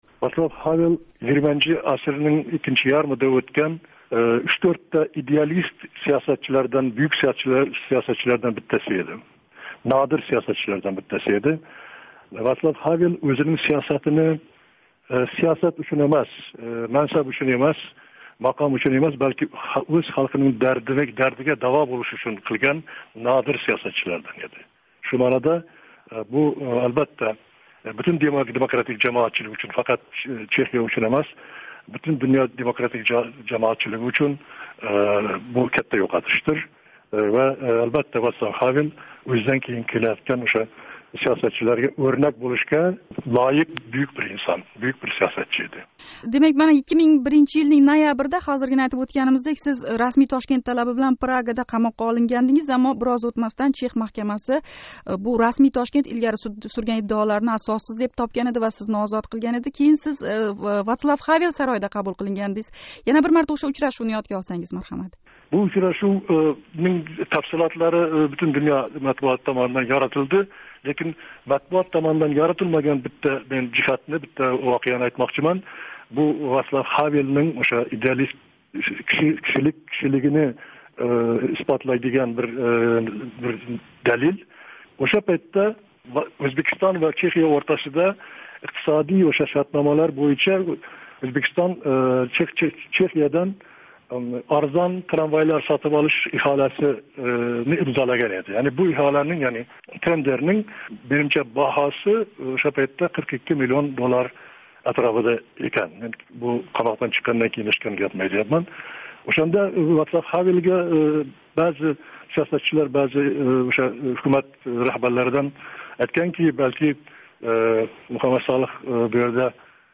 Эрк партияси ва ЎХҲ раҳбари Муҳаммад Солиҳ билан суҳбат.